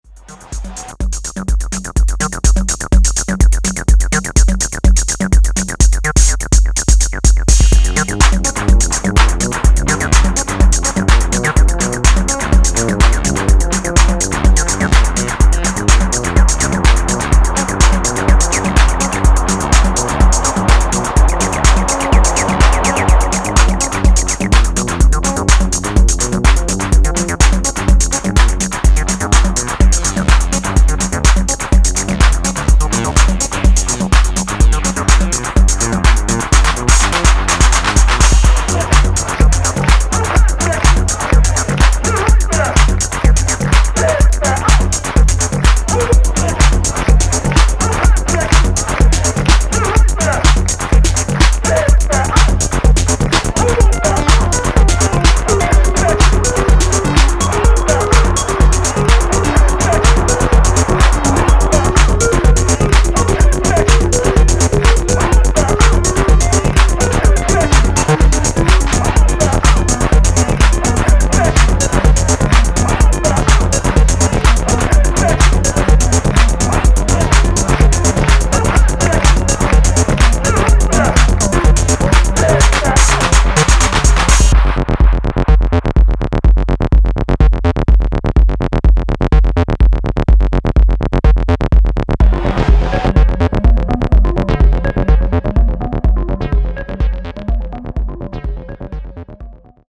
ぐつぐつ煮えたぎる303とタム/ハット